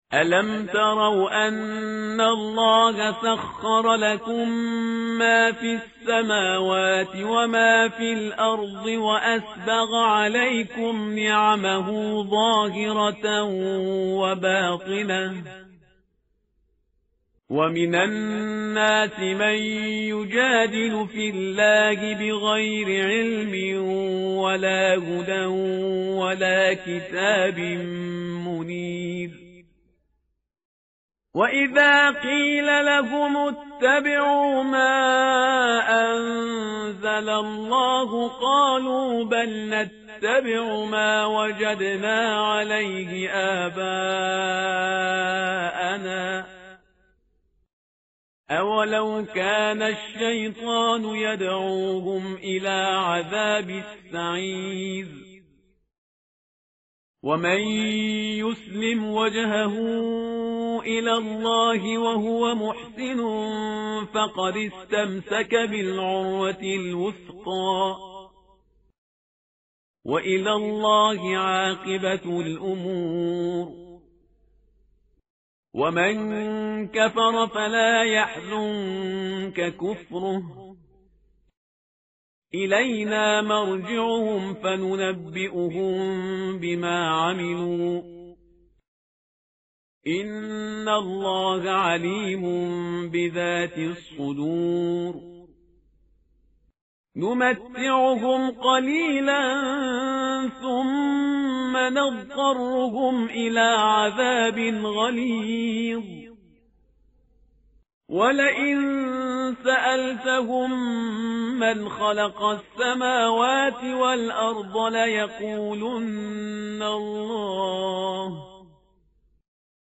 متن قرآن همراه باتلاوت قرآن و ترجمه
tartil_parhizgar_page_413.mp3